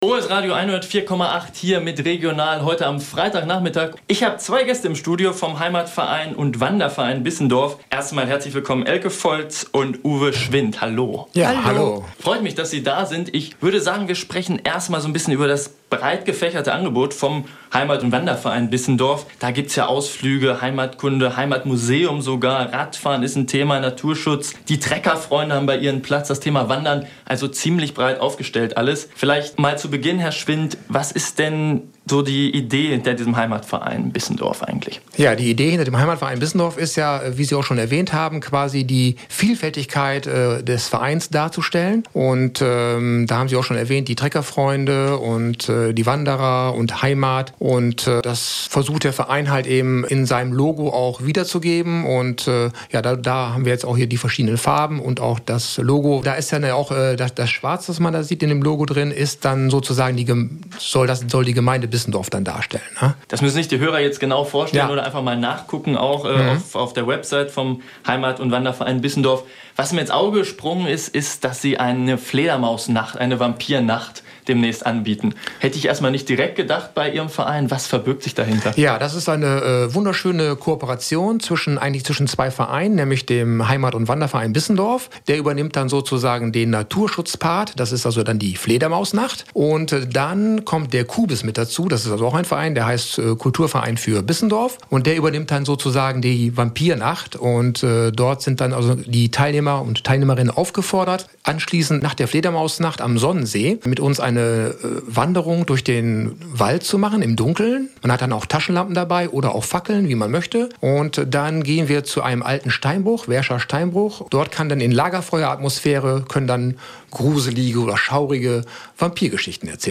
Die Radiosendung wurde um 16:00 Uhr ausgestrahlt.